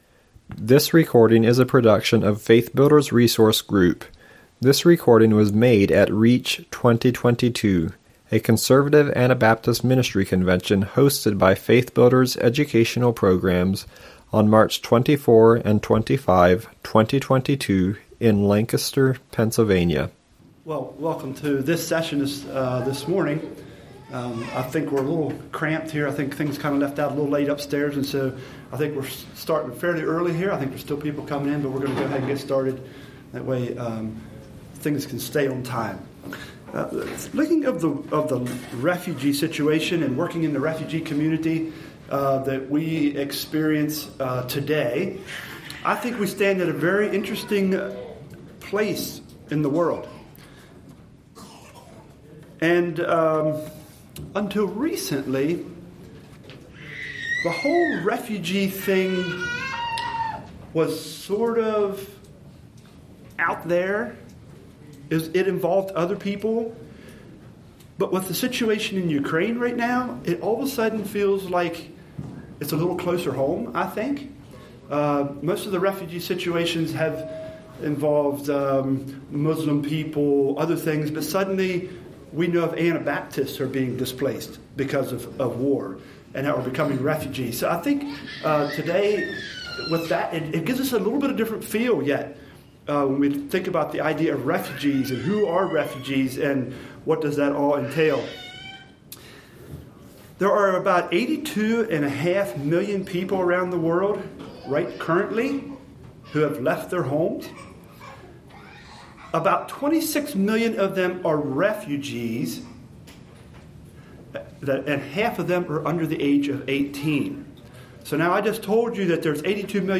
REACH 2022